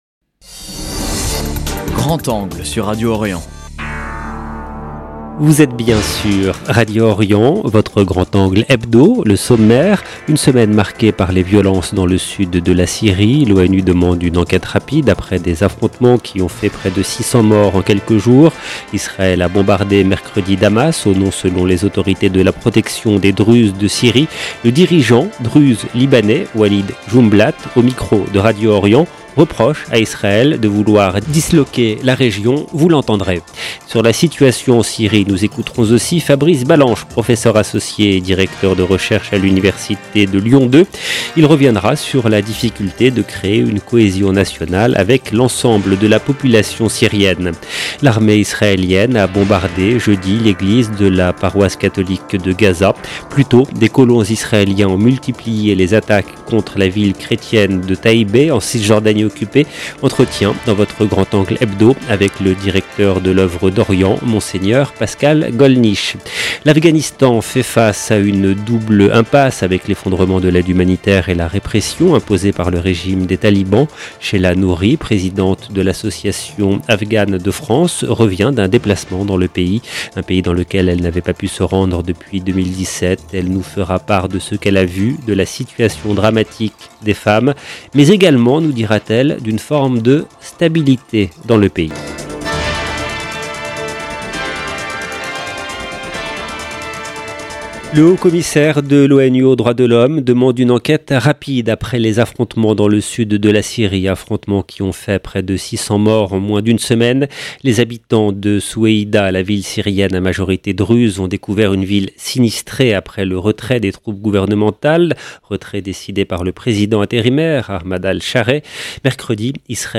Le dirigeant druze libanais Walid Joumblatt au micro de Radio Orient reproche à Israël de vouloir disloquer la région.